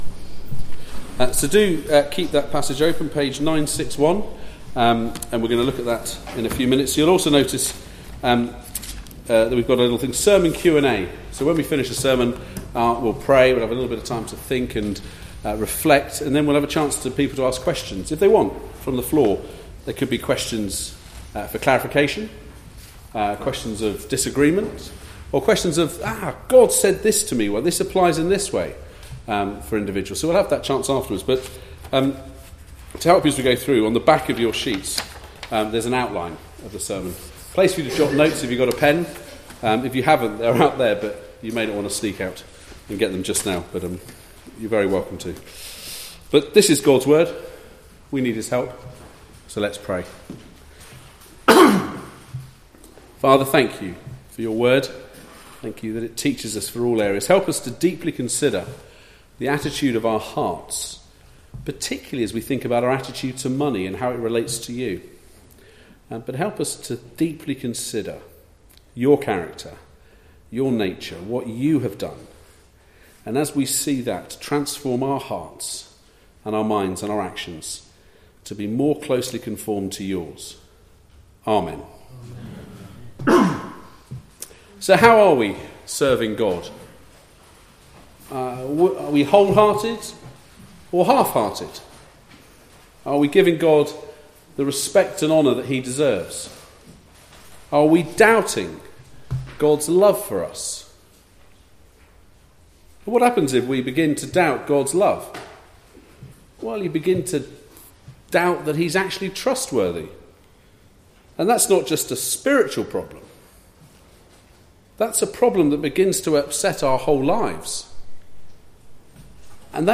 Malachi 3:6-12 Service Type: Weekly Service at 4pm Bible Text